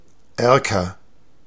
Ääntäminen
Synonyymit avancement acompte Ääntäminen France: IPA: [a.vɑ̃s] Haettu sana löytyi näillä lähdekielillä: ranska Käännös Ääninäyte Substantiivit 1.